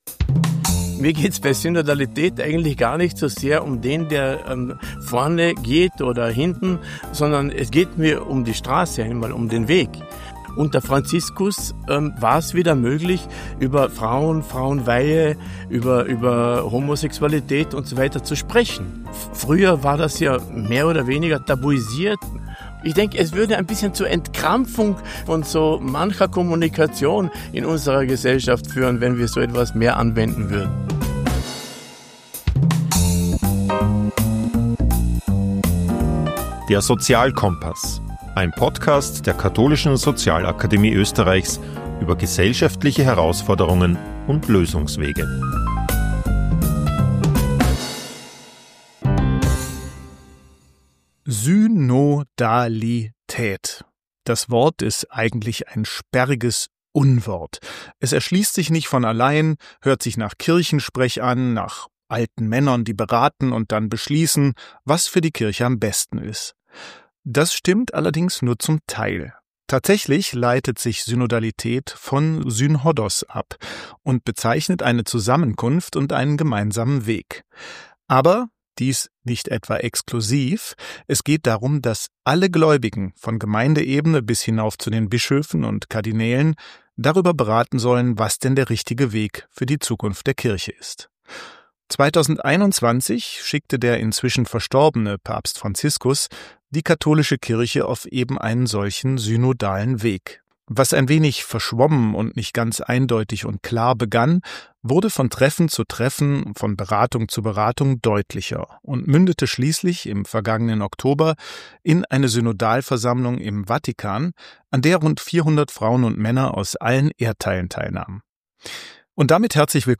Wir sprechen mit Bischof Josef Marketz über das Prinzip der Synodalität. Er erklärt den synodalen Weg als dialogische Entscheidungsfindungsmöglichkeit zwischen allen Beteiligten, unabhängig von ihrer Position in der kirchlichen Hierarchie. Außerdem thematisiert er, wie Synodalität auch außerhalb der Kirche angewandt werden kann und was ihm der synodale Weg persönlich bedeutet.